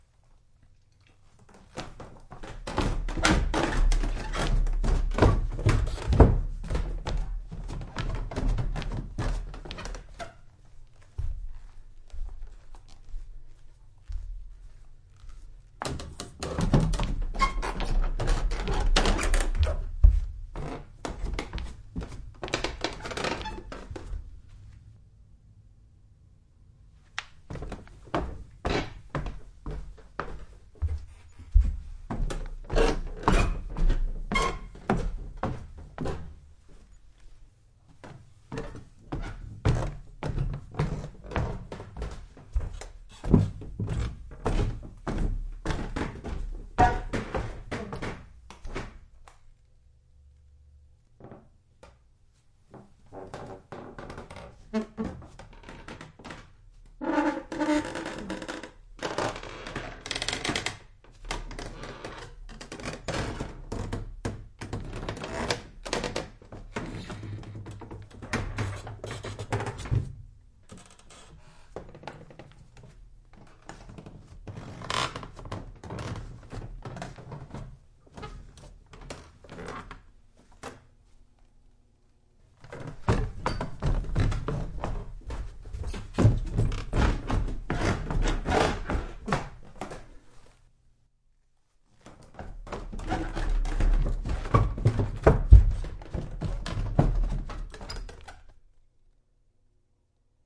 旧楼梯
描述：旧木楼梯听起来像那样。
Tag: 楼下 楼梯 楼梯 楼梯 上楼 楼梯